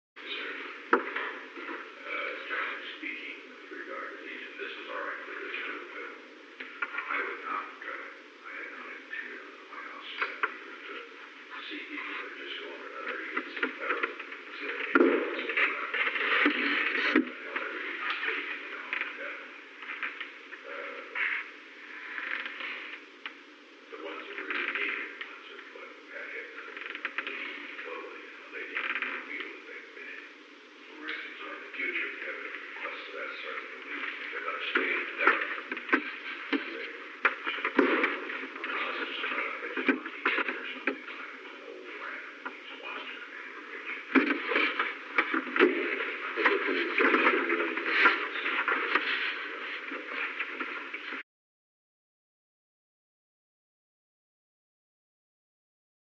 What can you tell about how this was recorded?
Recording Device: Oval Office The Oval Office taping system captured this recording, which is known as Conversation 872-005 of the White House Tapes.